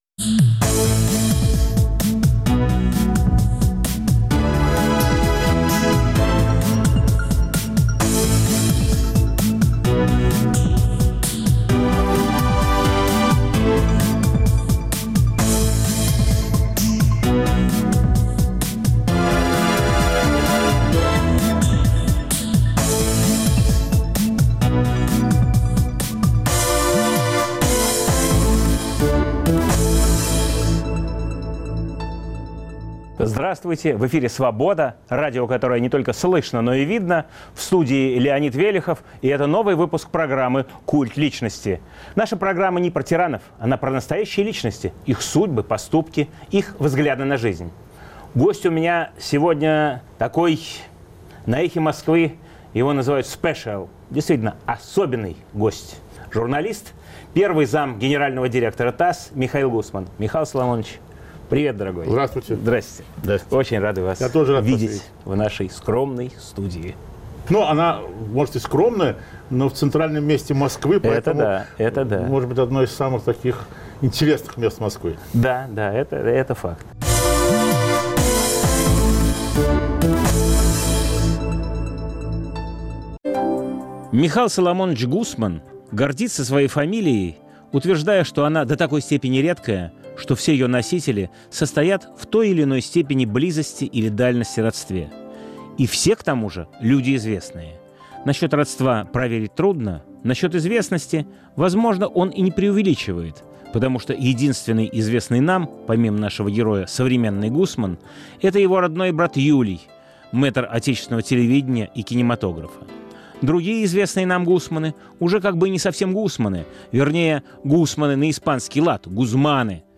Новый выпуск программы о настоящих личностях, их судьбах, поступках и взглядах на жизнь. В студии первый заместитель генерального директора ТАСС, журналист Михаил Гусман.